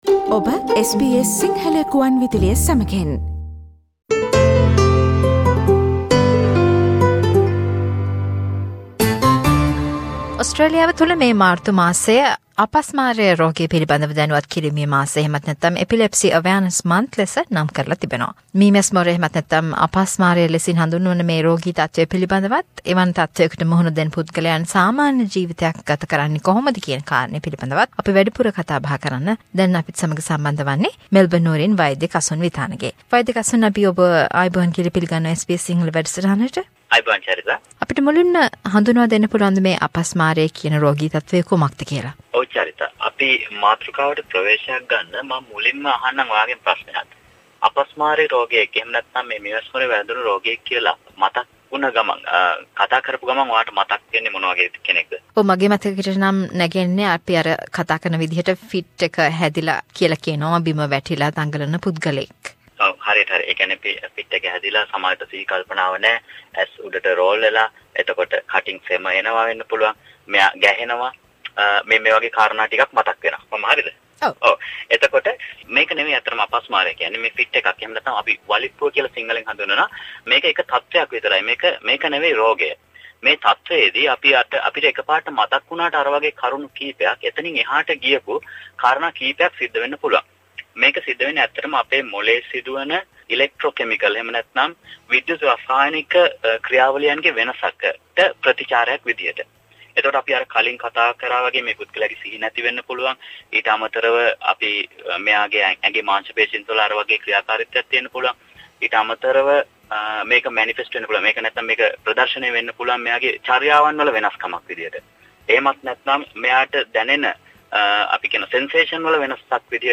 කෙටි සාකච්ඡාවට සවන්දෙන්න ....